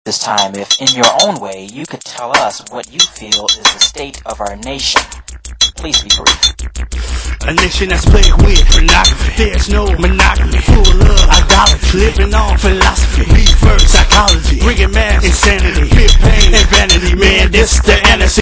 ....an influential member of the Holy Hip Hop movement!!
shirt-grabbing delivery